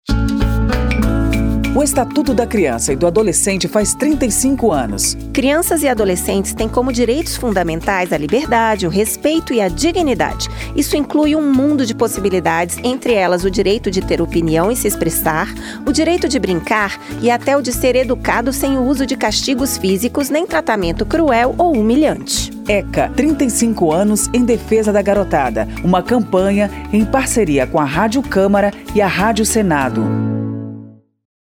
09-spot-eca-35-anos-parceiras.mp3